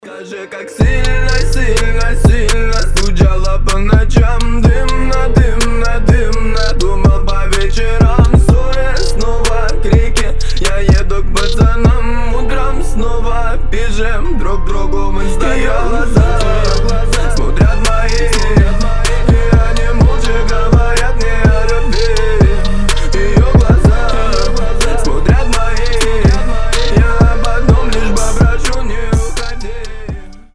• Качество: 320, Stereo
лирика
басы
медленные